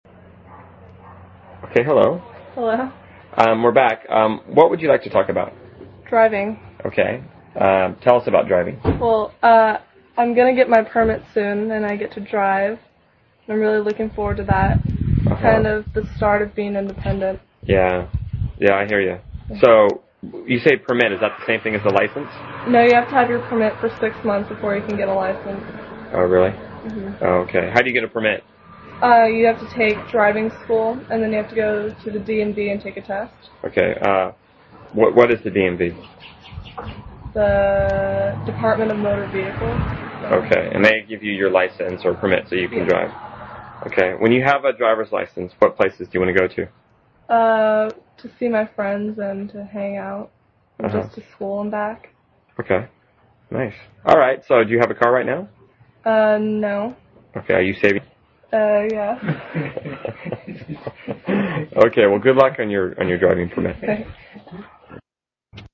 英语高级口语对话正常语速25:驾车（MP3）